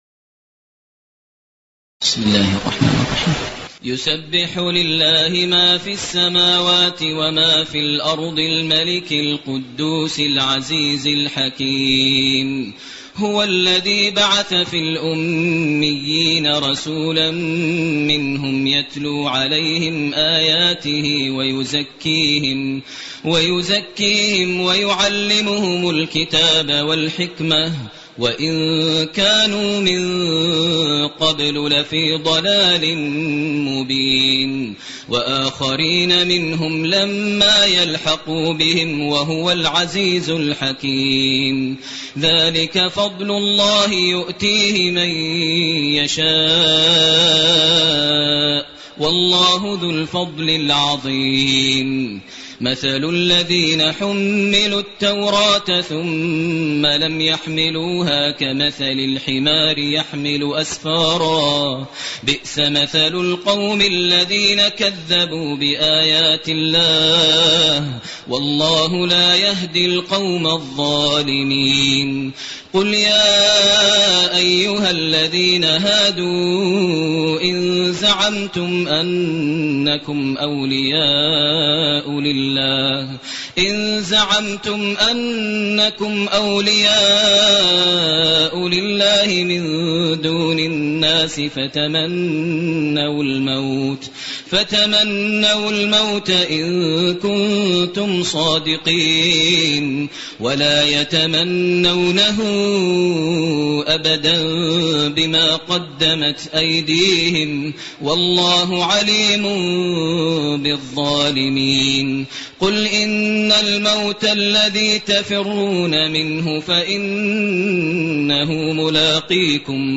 تراويح ليلة 27 رمضان 1429هـ من سورة الجمعة الى التحريم Taraweeh 27 st night Ramadan 1429H from Surah Al-Jumu'a to At-Tahrim > تراويح الحرم المكي عام 1429 🕋 > التراويح - تلاوات الحرمين